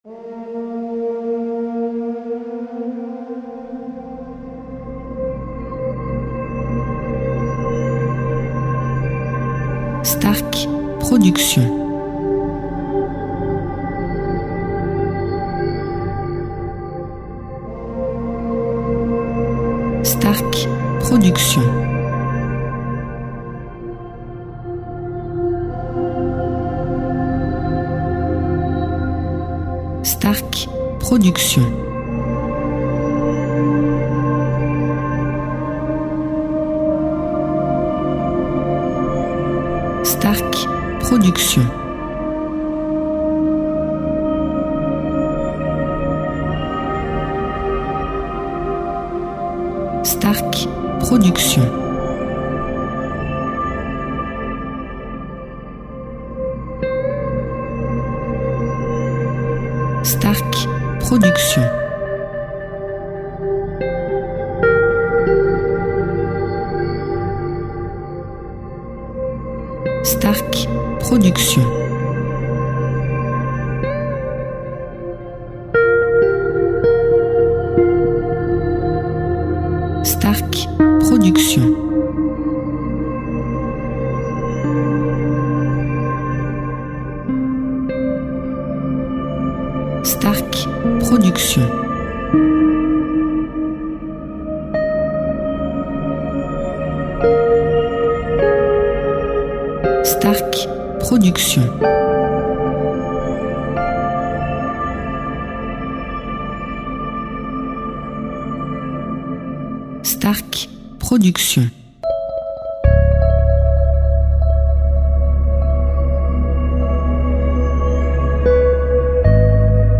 style NewAge Worldmusic durée 1 heure